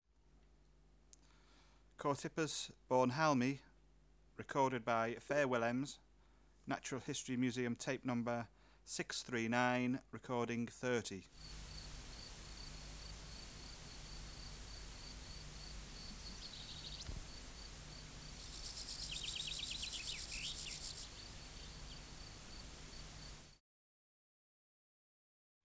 Species: Chorthippus (Glyptobothrus) bornhalmi
Air Movement: Nil Light: Intermittent sun Extraneous Noise: Birds Biotic Factors / Experimental Conditions: Isolated male
Microphone & Power Supply: AKG D202 E (LF circuit off)
Recorder: Uher 4200